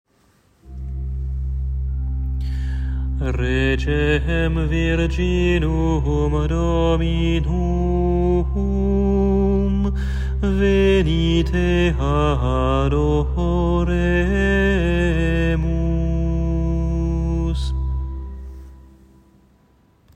Antienne invitatoire (mémoires) : Regem virginum [partition LT]